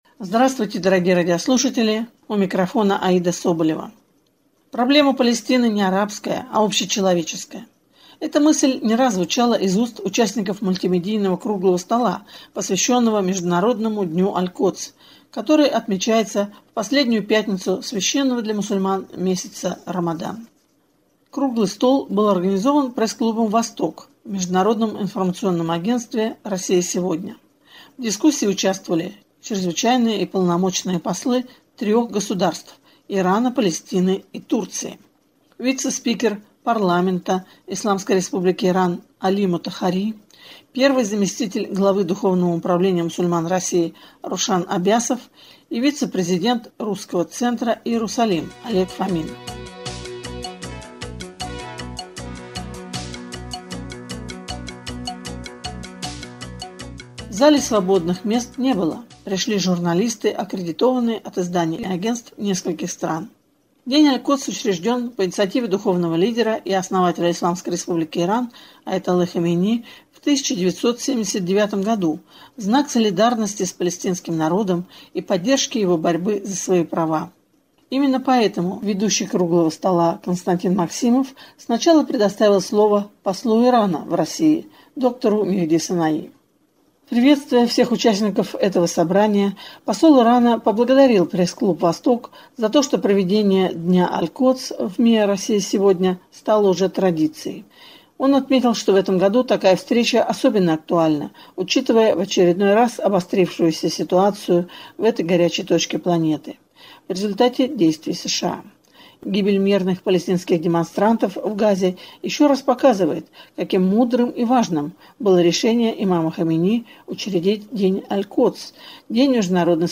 Круглый стол был организован Пресс-клубом «Восток» в МИА «Россия сегодня».
В зале свободных мест не было ¬ – пришли журналисты, аккредитованные от изданий и агентств нескольких стран.